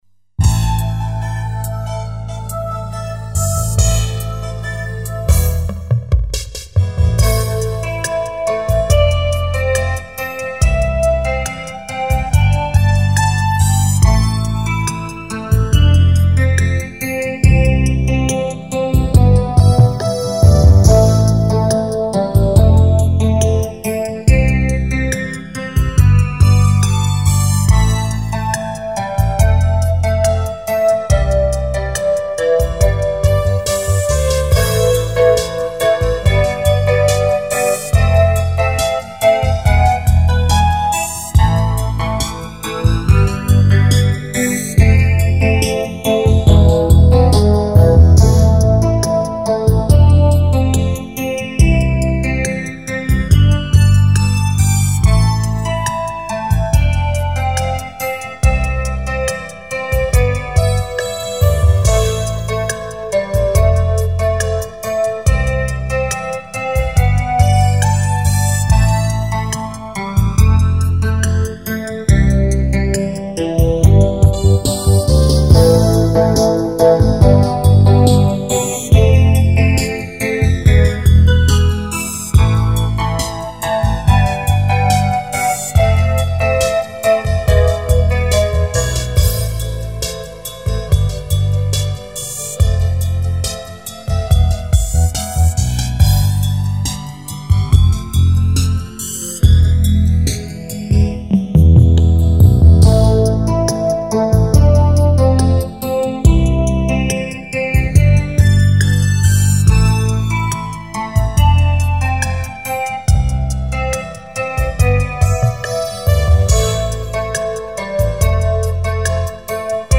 - remix (MP3, качество: 128kbps 44kHz, размер 2.77mb)